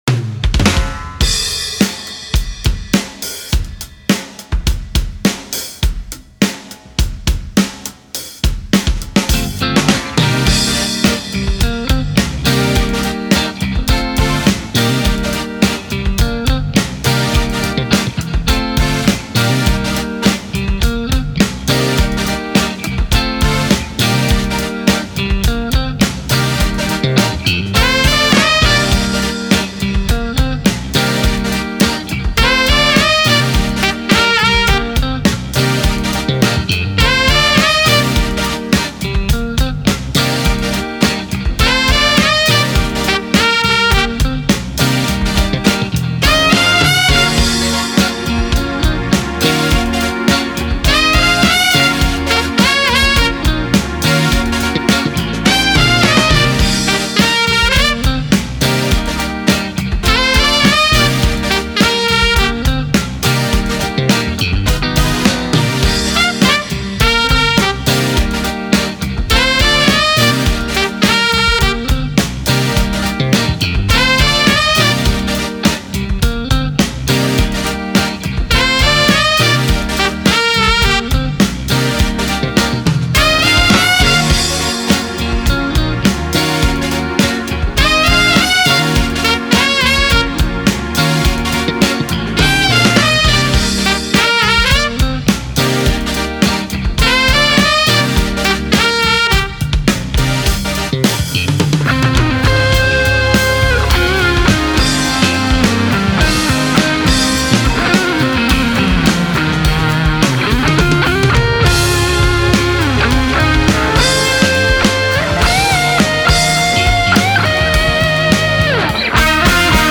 Funk-Jazz